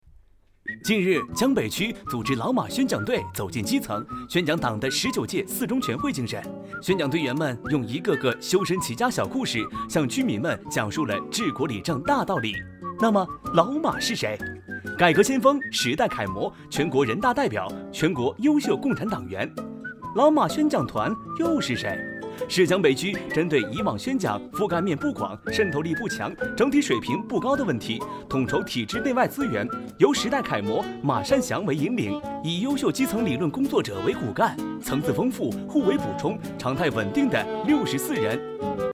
特点：年轻时尚 轻松幽默 MG动画
风格:欢快配音
31男127系列-【MG动画】—宣讲队.mp3